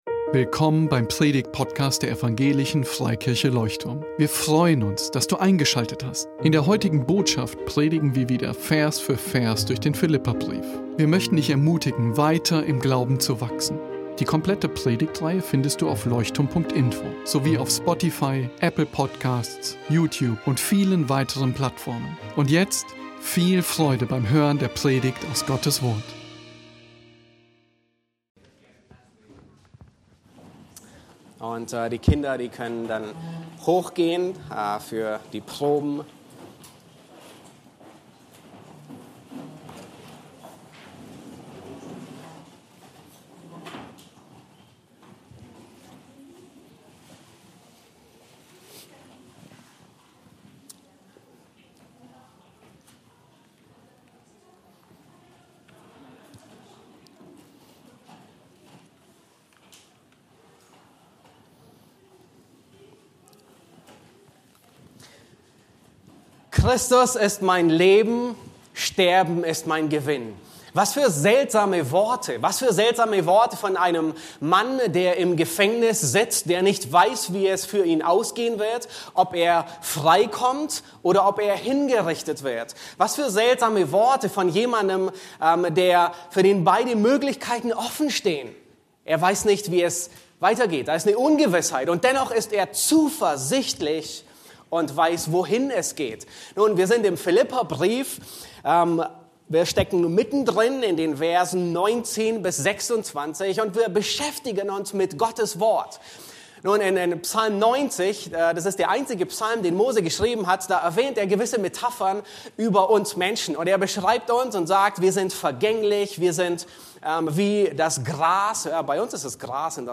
Leuchtturm Predigtpodcast Podcast